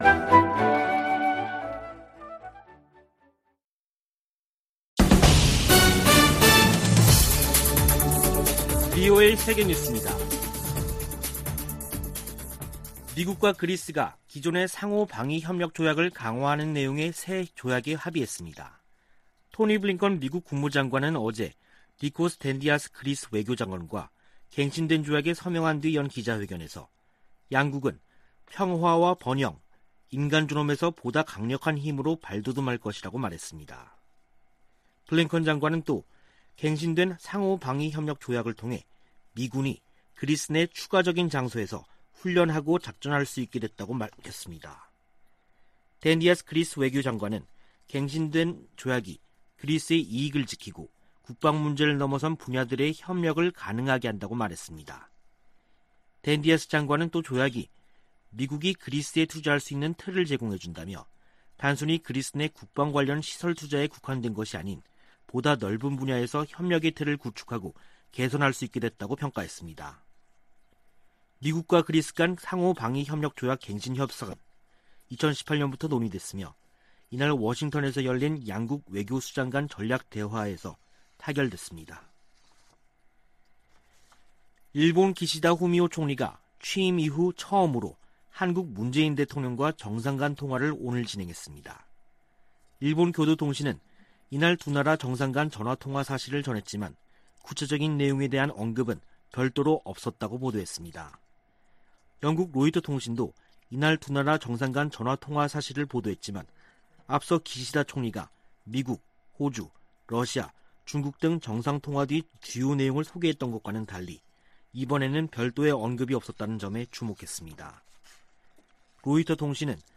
VOA 한국어 간판 뉴스 프로그램 '뉴스 투데이', 2021년 10월 15일 3부 방송입니다. 미국이 탈퇴 3년 만에 유엔 인권이사회 이사국으로 선출됐습니다. 미 국무부가 북한 핵 문제 해결을 위해 동맹과 활발한 외교를 펼치고 있다고 밝혔습니다. 미국과 한국 등 30여개국이 세계적으로 증가하는 랜섬웨어 공격 대응에 적극 공조하기로 했습니다.